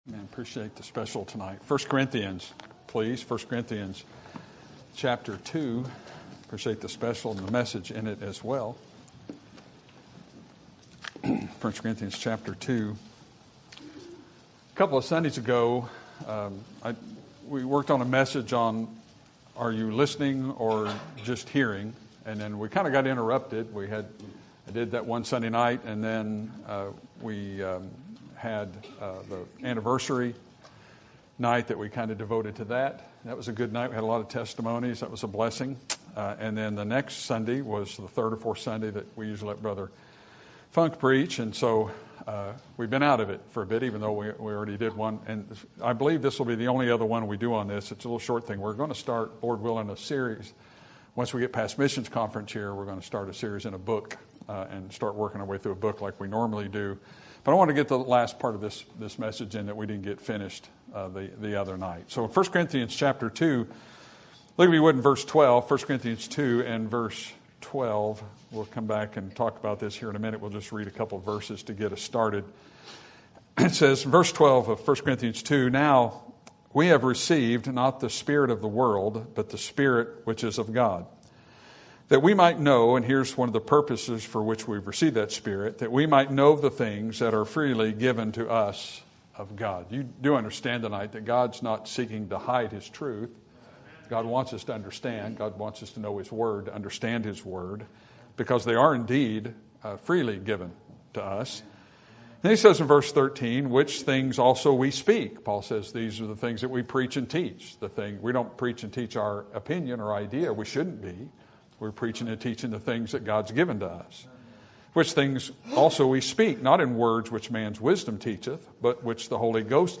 Sermon Topic: General Sermon Type: Service Sermon Audio: Sermon download: Download (27.2 MB) Sermon Tags: 1 Corinthians Listen Hear Preaching